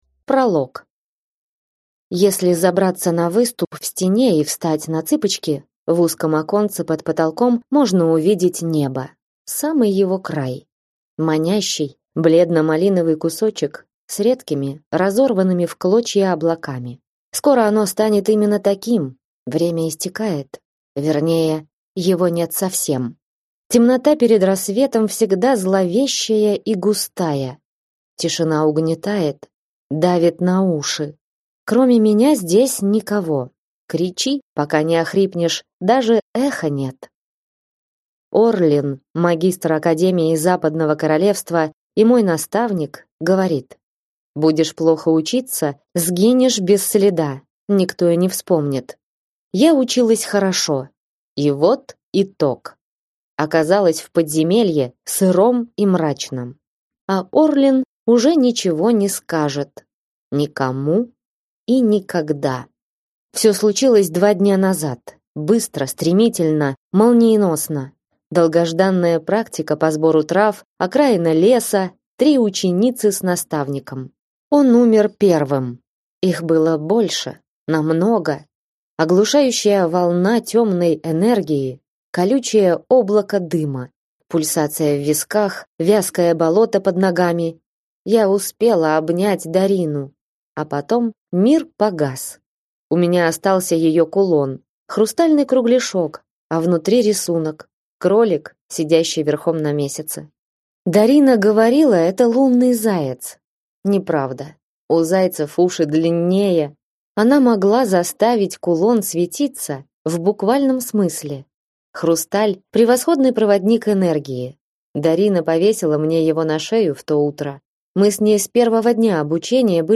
Аудиокнига Живой ты не вернешься. Книга 1 | Библиотека аудиокниг